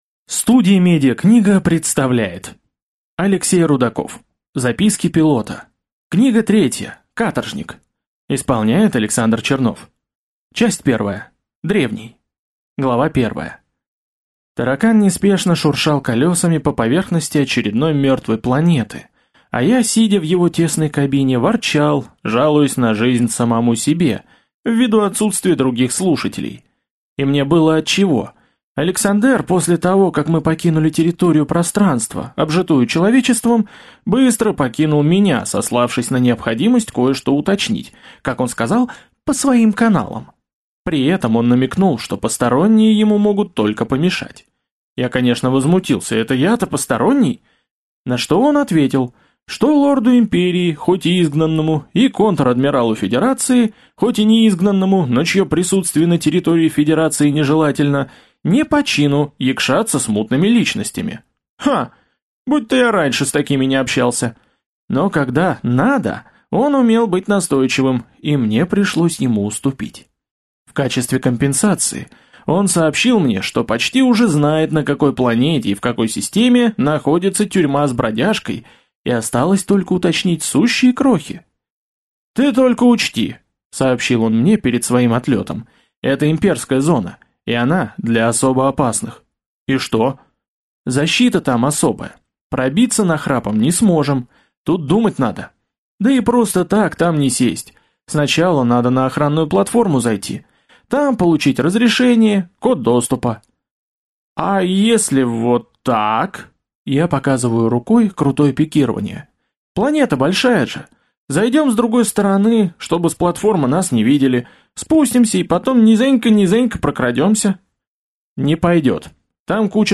Аудиокнига Каторжник | Библиотека аудиокниг